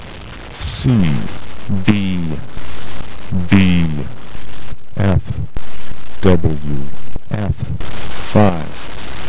Un estratto dall'evento per la business community organizzato da HSM, giunto alla sua quarta edizione italiana.